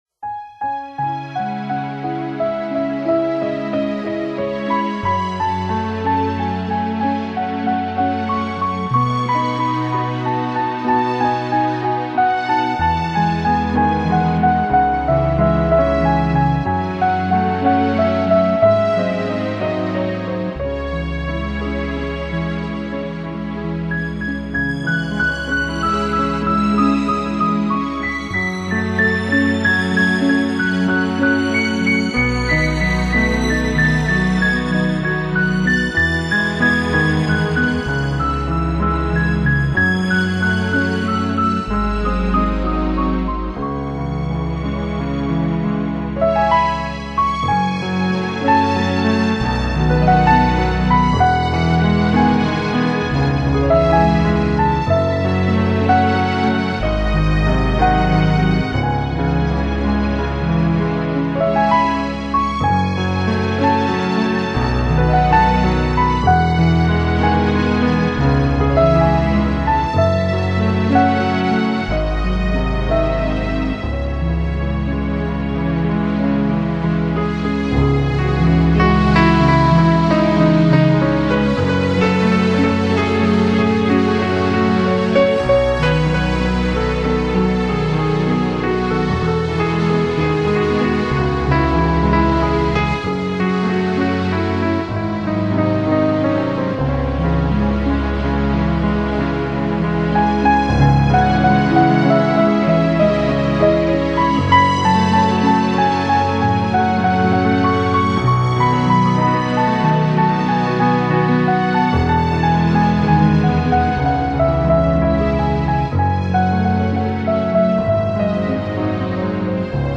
Genre: New Age, Instrumental